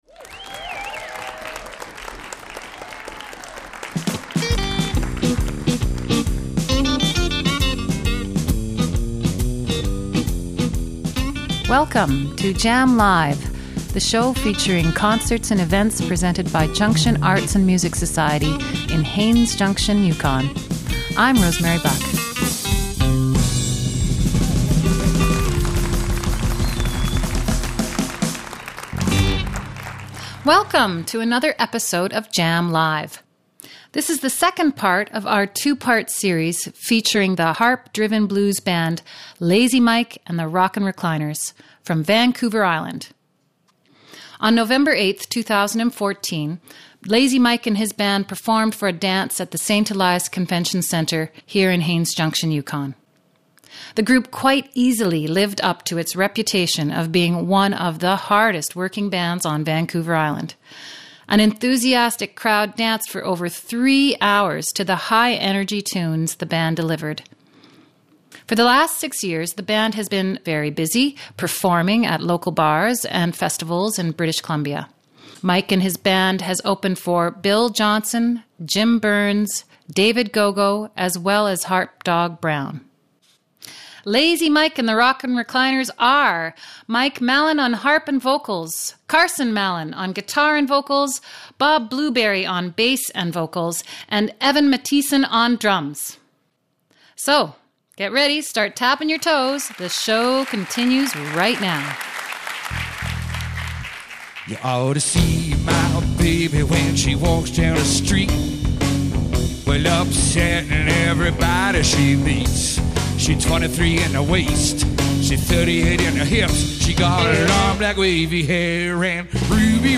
Live music recorded in Yukon communities and beyond.